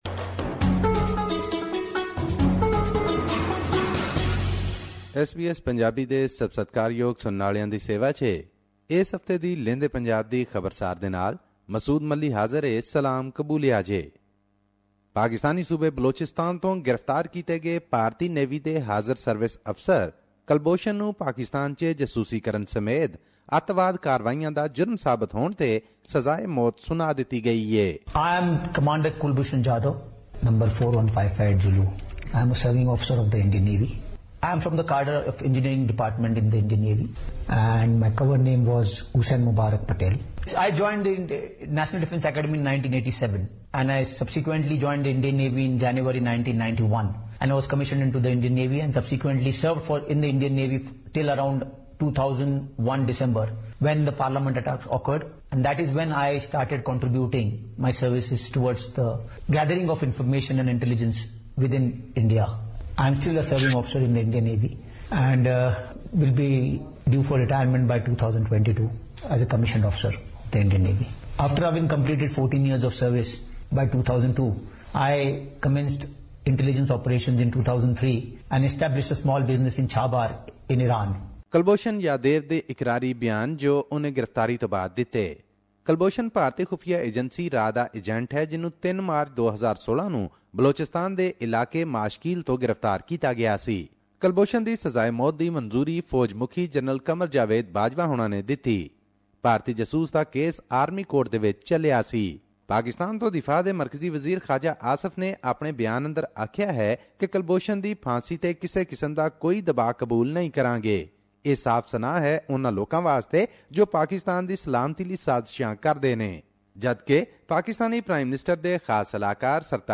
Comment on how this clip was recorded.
Here's the podcast in case you missed hearing it on the radio.